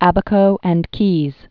(ăbə-kō; kēz, kāz)